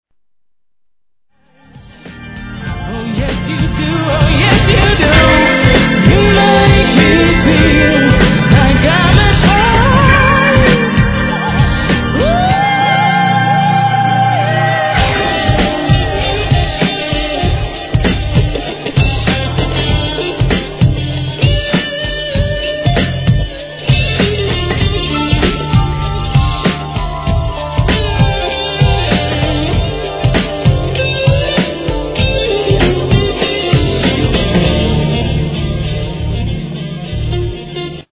1. HIP HOP/R&B
パワフルなVOCALで聴かせる'90sグランド・ビート!!